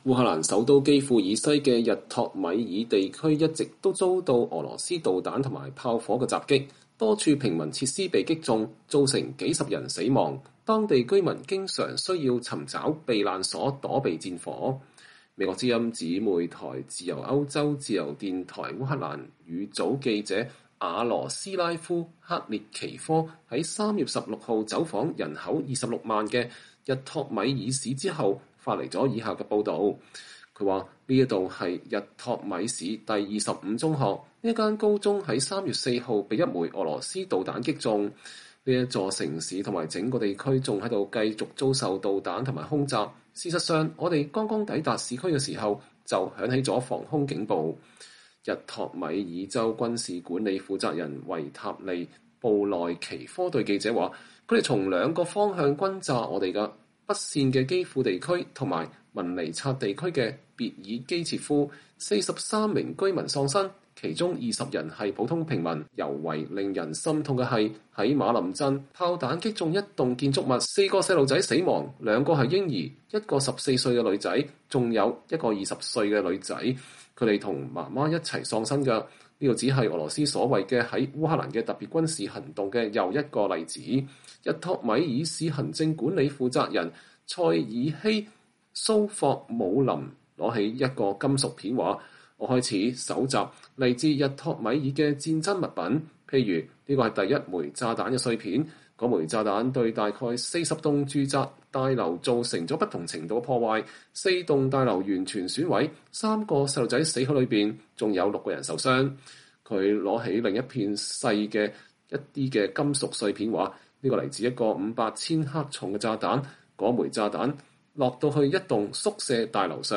記者實地直擊：俄軍狂轟濫炸下的烏克蘭日托米爾地區